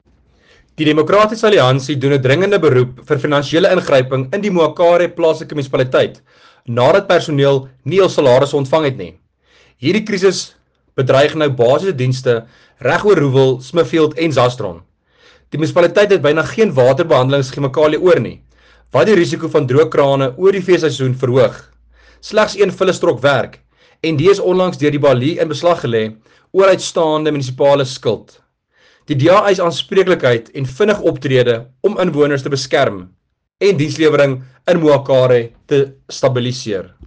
Afrikaans soundbite by Werner Pretorius MPL, and